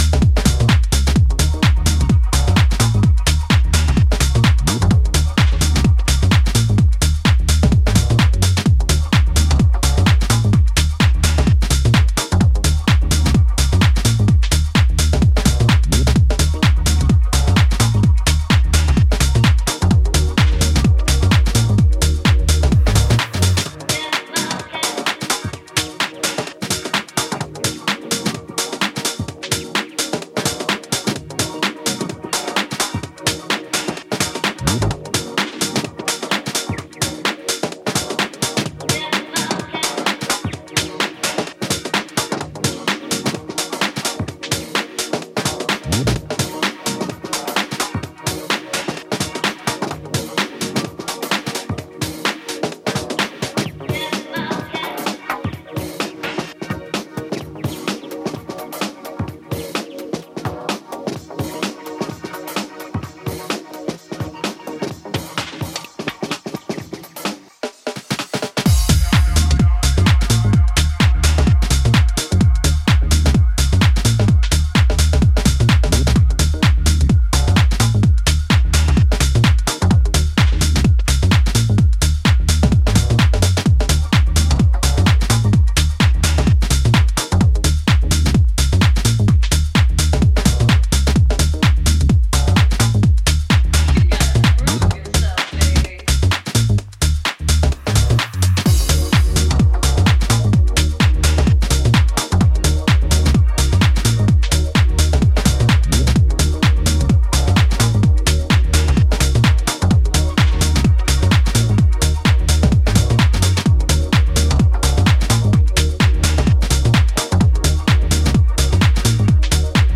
shuffling modular percussion and a menacing baseline